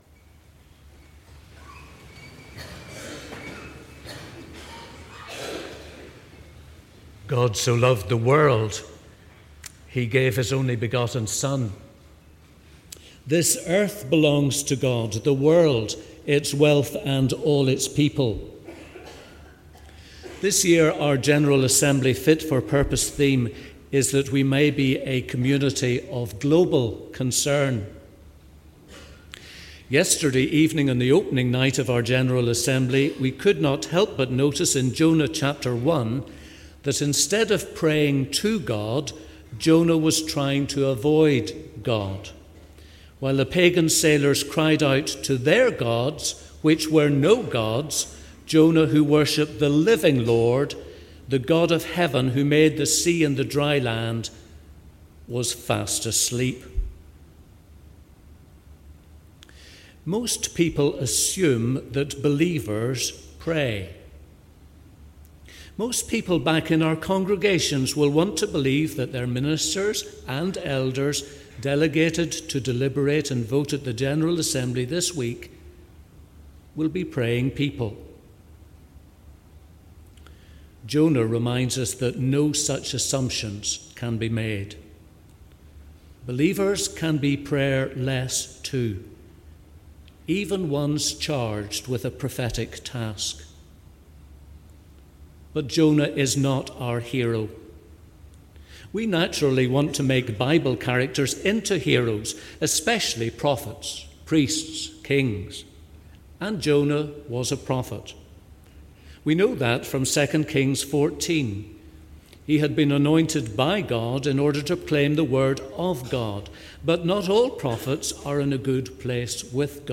Address of the Moderator, Dr Frank Sellar, at the Communion Service of the 2016 General Assembly.
The Assembly met in Assembly Buildings, Belfast from Monday, 6th June until Friday, 10th June, 2016.